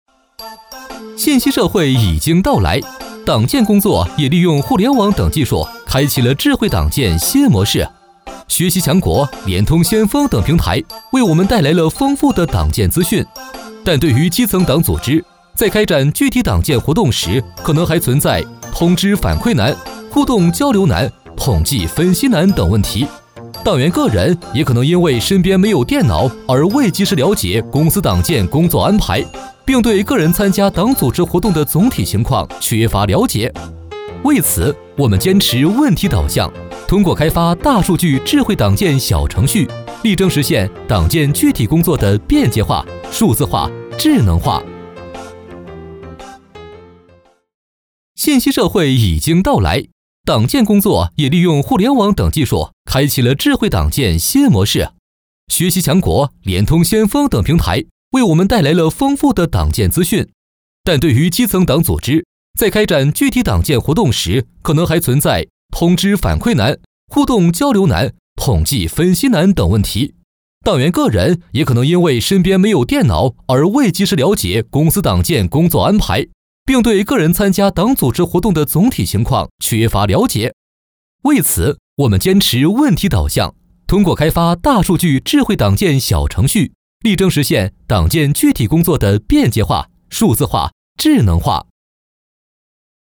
飞碟说-男52-年轻活力 智慧党建.mp3